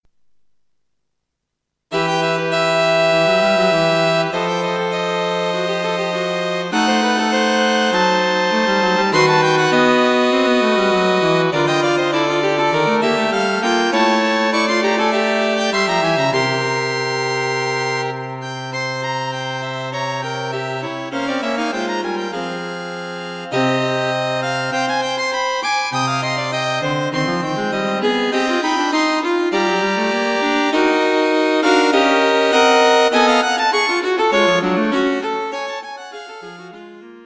String Quartet for Concert performance
A suite of three, short modern pieces. 1. Moderato 2.